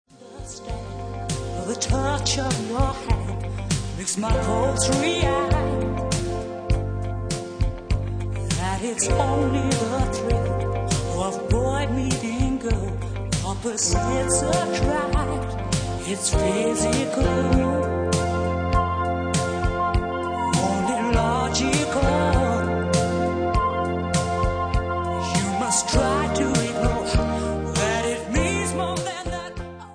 R&B KARAOKE MUSIC CDs
w/vocal